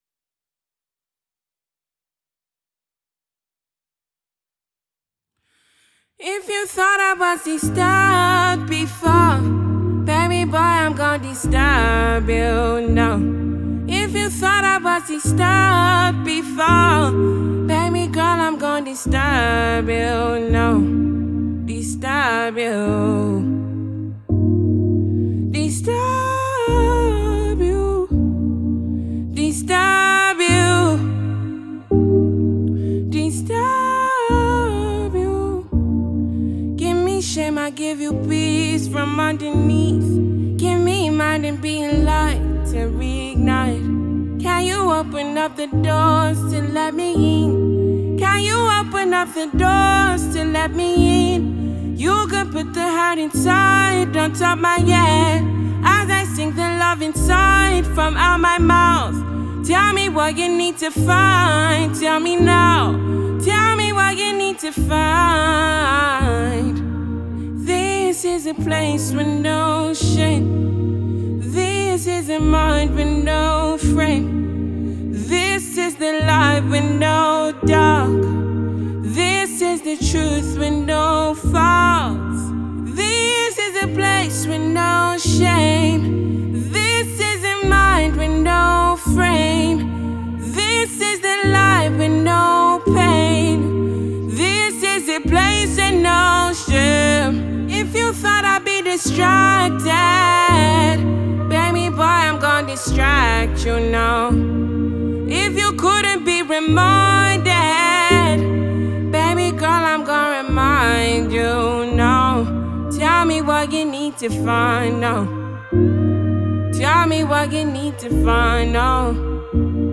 Nigerian alt-R&B singer, songwriter and record producer